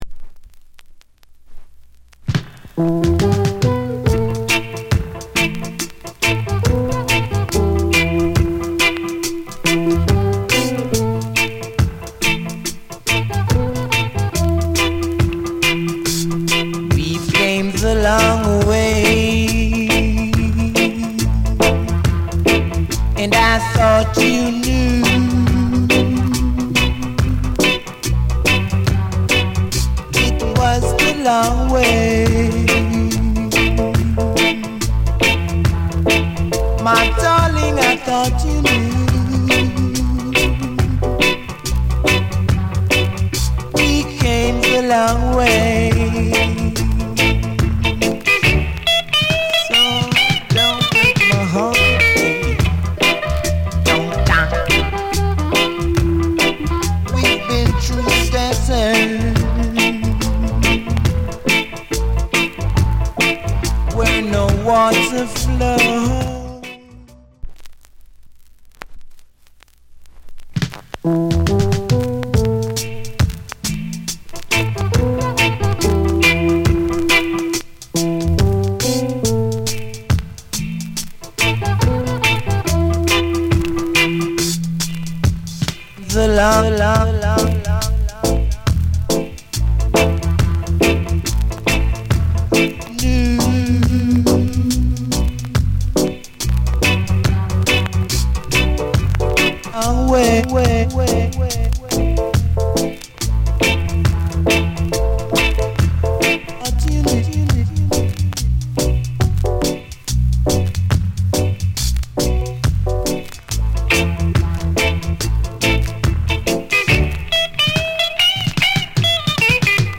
Reggae70sMid / Male Vocal